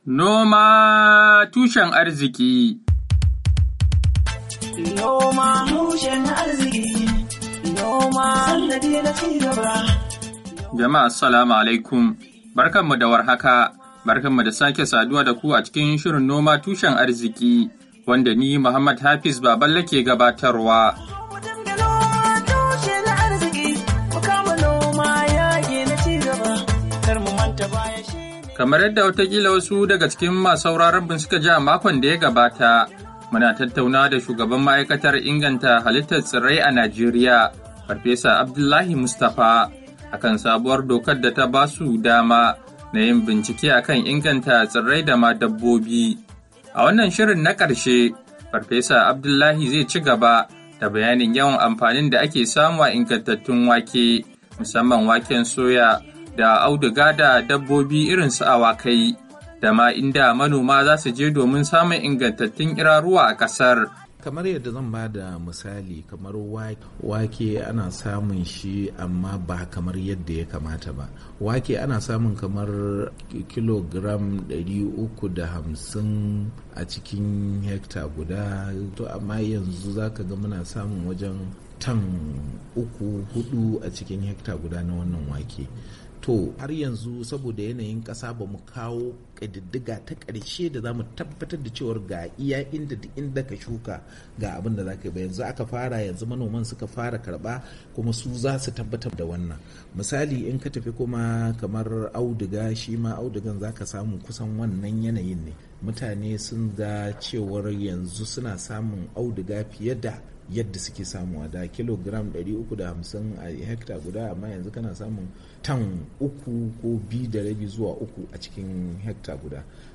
Shirin noma tushen arziki na wannan makon, zai daura a inda muka tsaya a tattaunawa da shugaban Ma'aikatar Inganta Halittar Tsirrai Ta Najeriya Farfesa Abdullahi Mustapha kan ayyukan ma'aikatar bayan da shugaban Najeriya Muhammadu Buhari ya sanya hannun kan dokar da za ta ba dama na bunkasa abubuwa da suka jibanci iri da noma.